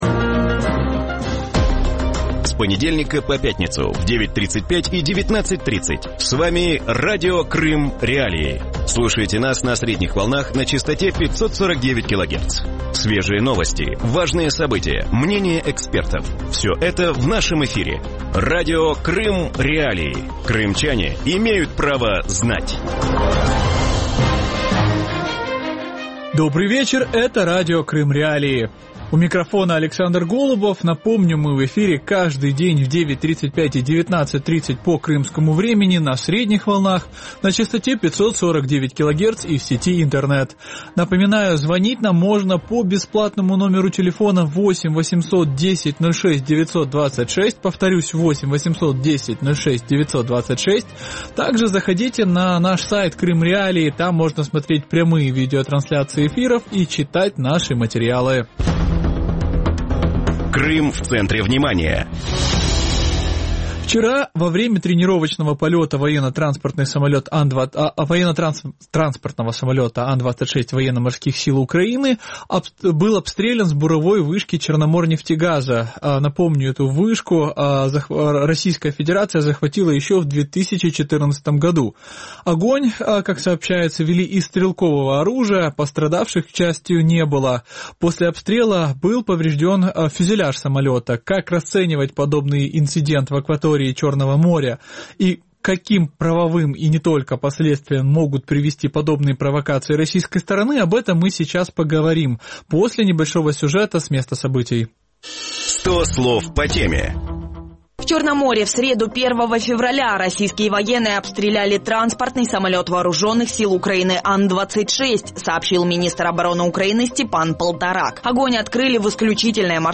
В вечернем эфире Радио Крым.Реалии говорят про обстрел украинского военно-транспортного самолета АН-26 над Черным морем. Над чьей территорией был атакован украинских самолет, к каким последствиям может привести обстрел и как Украина должна реагировать на военные провокации?